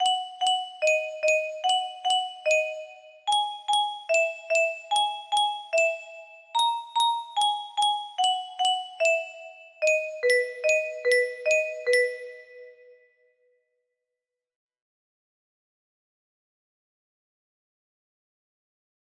Cheer up baby music box melody